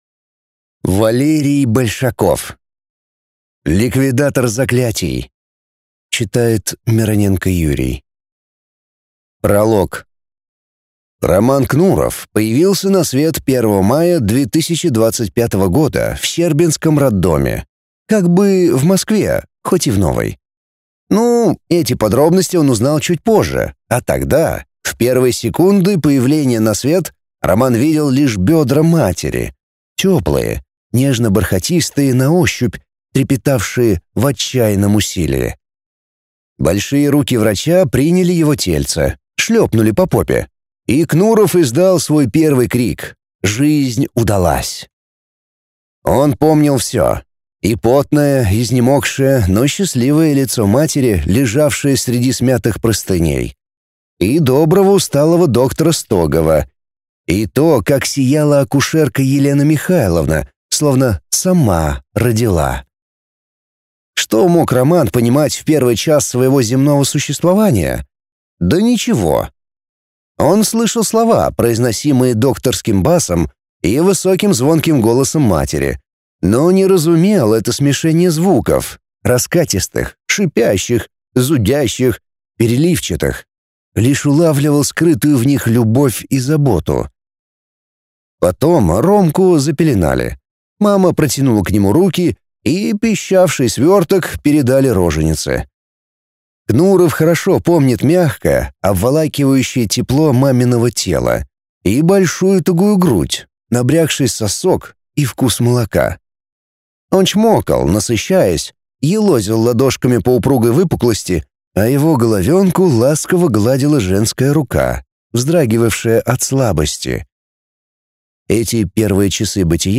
Аудиокнига Ликвидатор заклятий | Библиотека аудиокниг